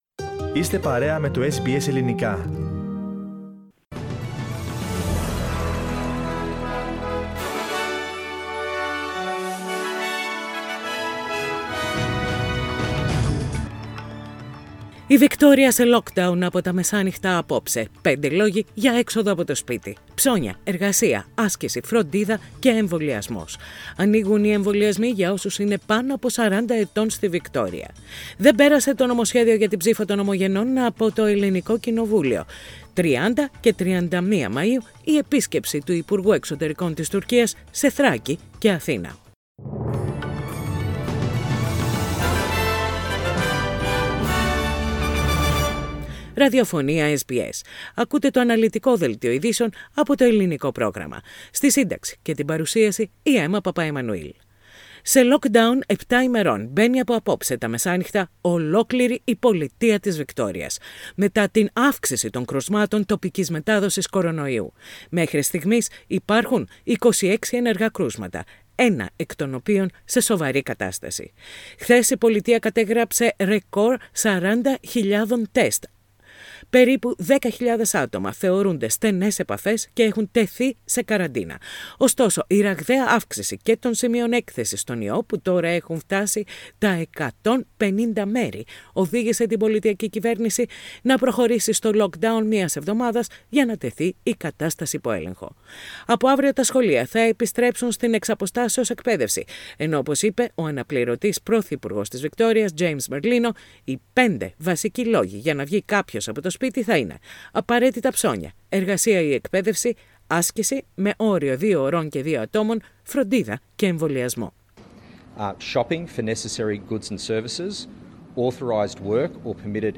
News in Greek - Thursday 27.5.21